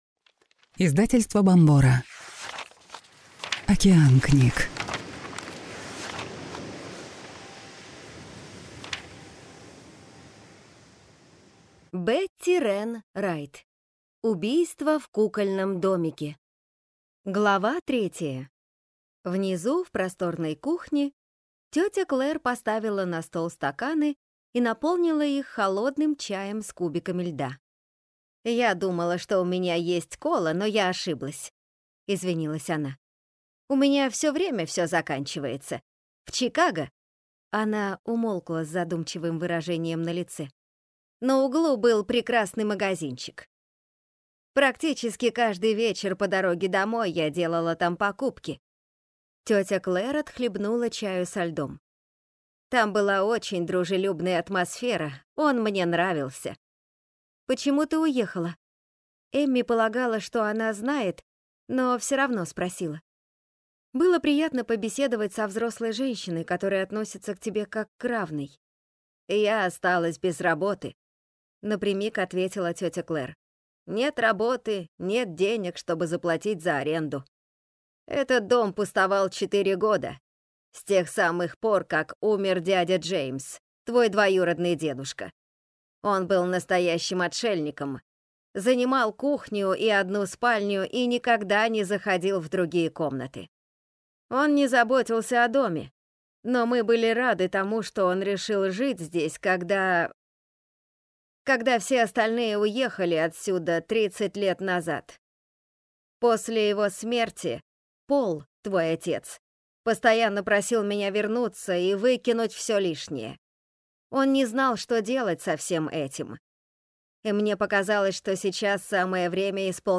Аудиокнига Убийства в кукольном домике | Библиотека аудиокниг
Прослушать и бесплатно скачать фрагмент аудиокниги